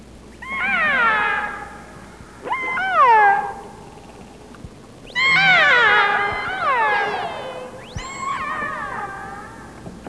Everything Alaska / Wildlife Voices
whale.aiff